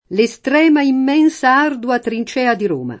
trincea [trin©$a] s. f. — es. con acc. scr.: L’estrema, immensa, ardua trincèa di Roma [